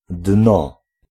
Ääntäminen
Ääntäminen Tuntematon aksentti: IPA: /dnɔ/ Haettu sana löytyi näillä lähdekielillä: puola Käännös Konteksti Ääninäyte Substantiivit 1. bottom slangi, brittienglanti US 2. ground US Suku: n .